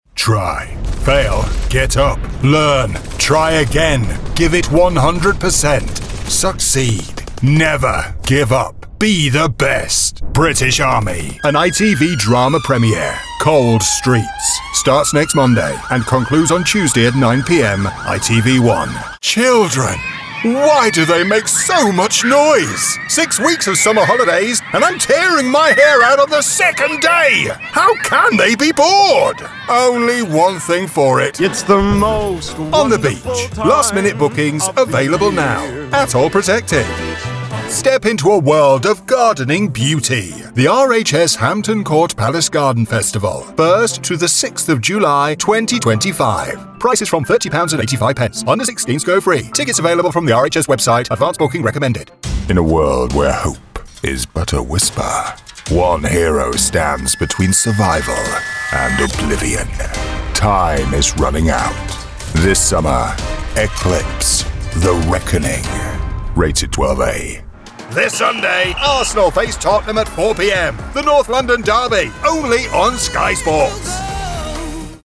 Naturally deep, warm and friendly, distinguished, charismatic, versatile
Commercial